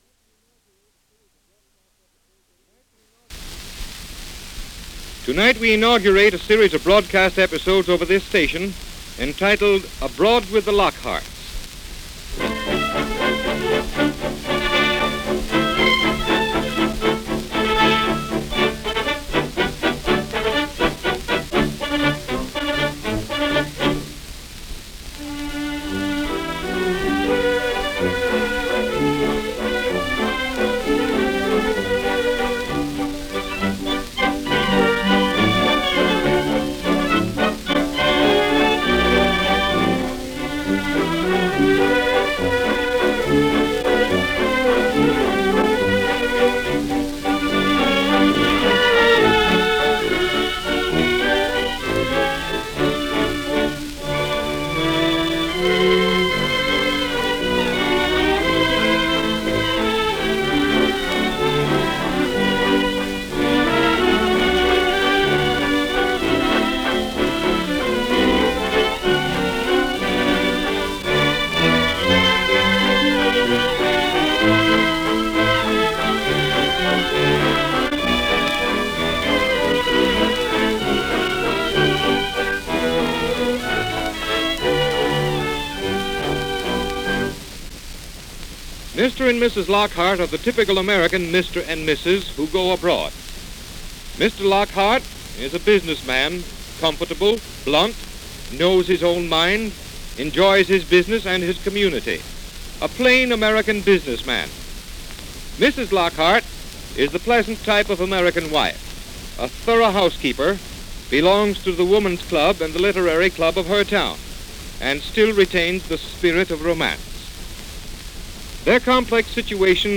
This first installment introduces listeners to Will and Mrs. Lockhart, portrayed by Gene and Kathleen Lockhart, as they set the stage for their European adventure. In this episode, Mrs. Lockhart, the romantic and adventurous half of the couple, persuades her pragmatic husband Will to ditch his usual fishing trip for their tenth wedding anniversary and instead embark on a grand vacation to Europe. Will, a businessman with a dry wit and a preference for simplicity, is initially resistant, but his wife’s enthusiasm wins out.